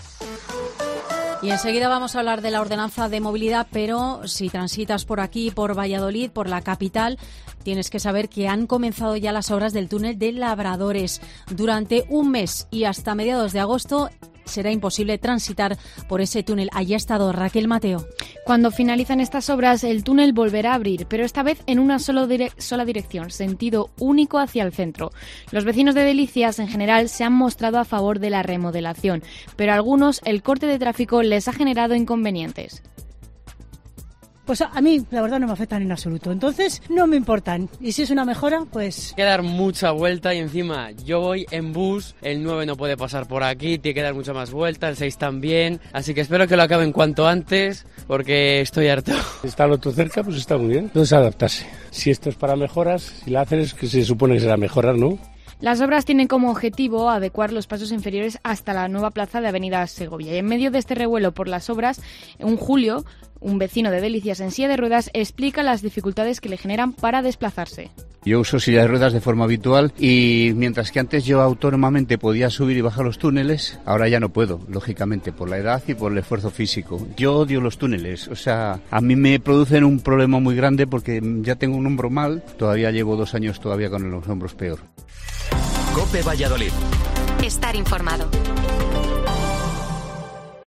Los vecinos de Labradores ante el cierre del túnel durante un mes